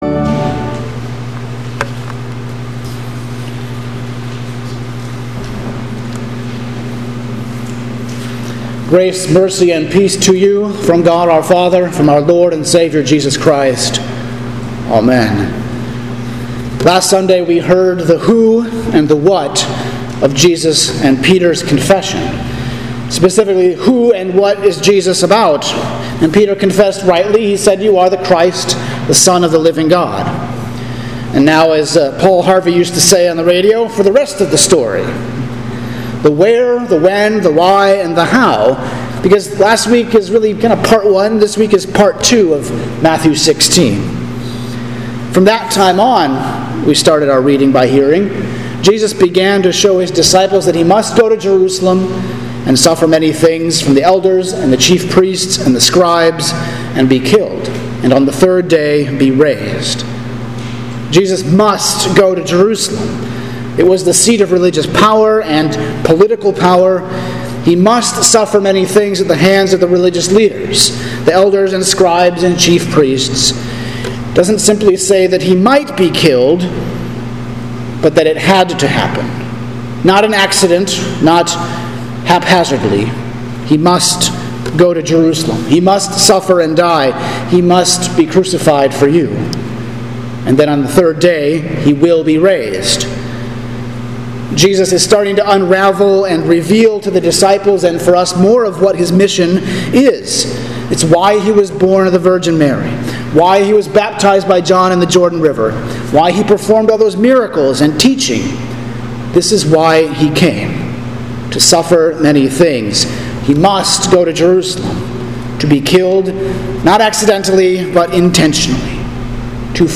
Sermon for Pentecost 13 – August 30th, 2020